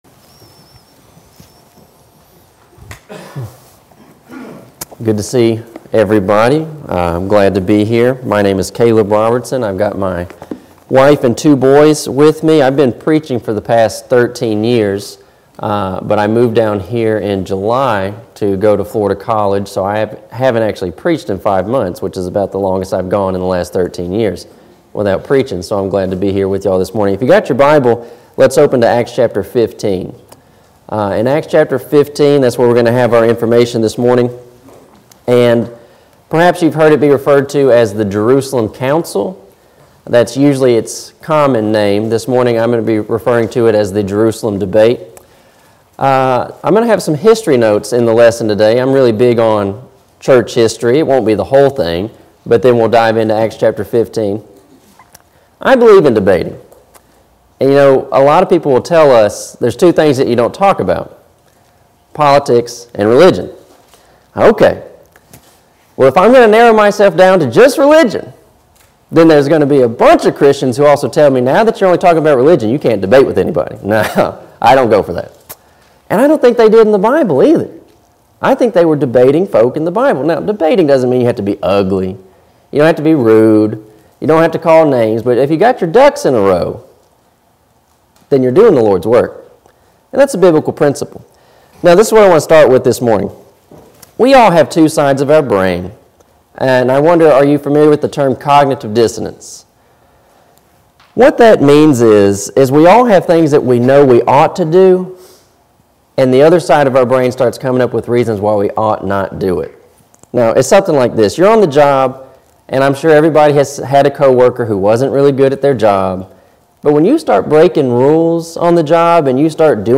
Sun AM Worship – Acts 15